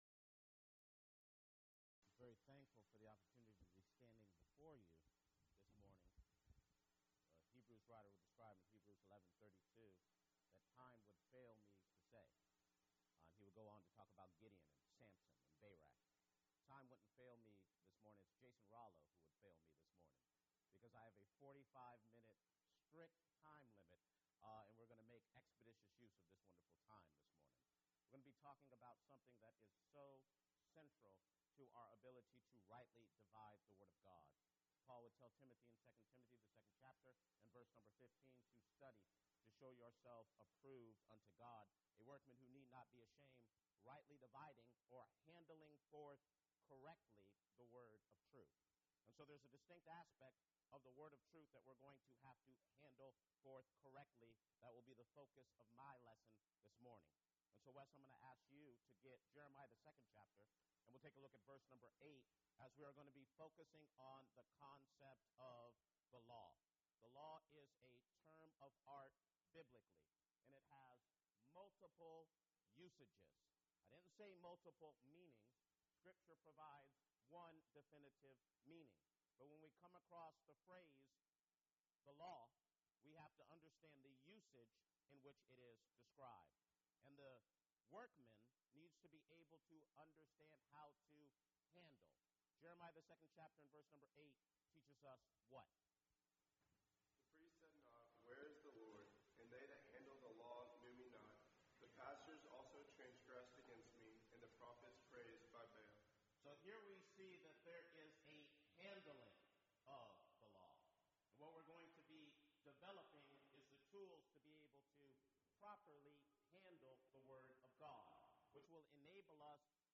Event: 4th Annual Men's Development Conference